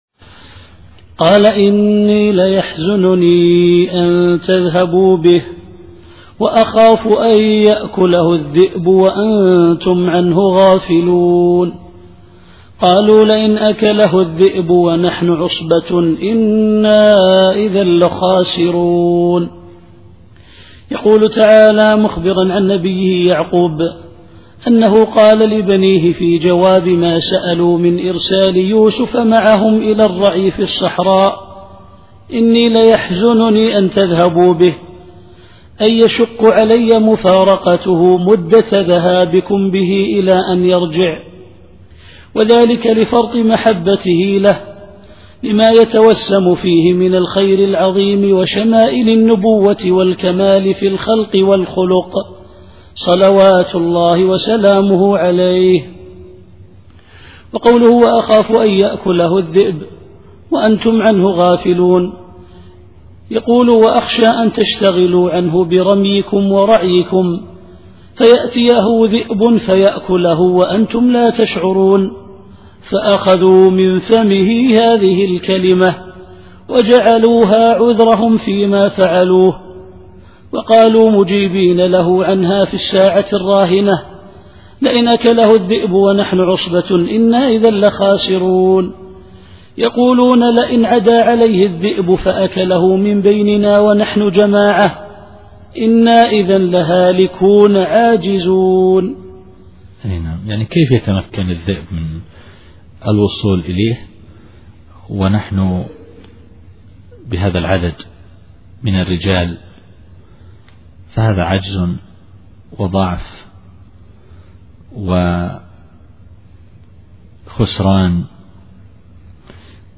التفسير الصوتي [يوسف / 14]